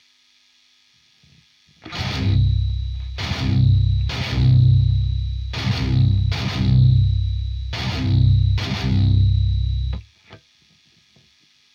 Ich habe sehr verstaerkte Raummoden-aehnliche Nachhalleffekte [mehr in den Hoehen: nach dem Abdaempfen!
In Tonocracy habe ich dieses Pfeiffgeraeusch nicht beim Abdaempfen.